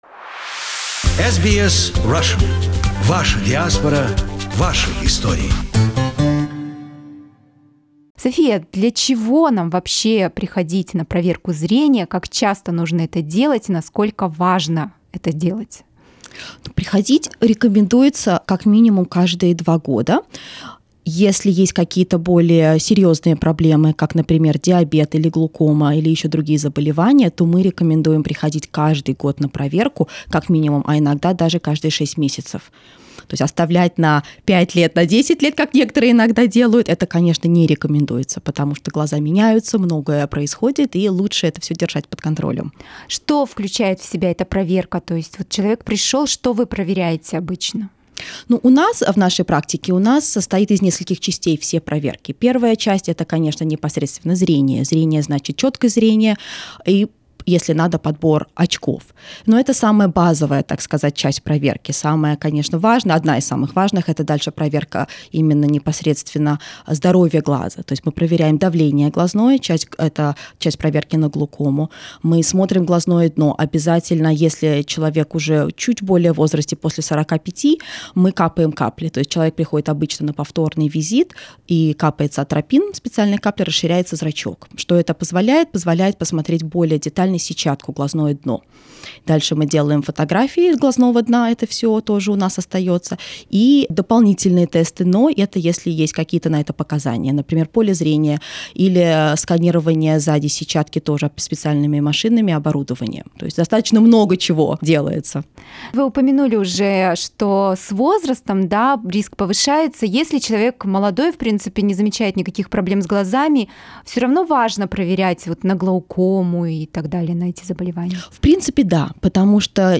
Conversation with an optometrist: Keeping your eyes healthy